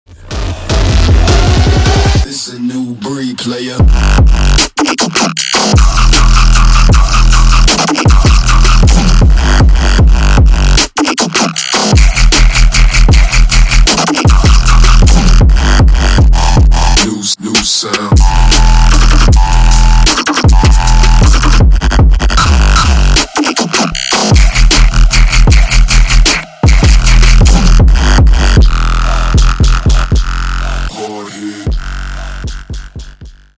Громкие Рингтоны С Басами
Рингтоны Электроника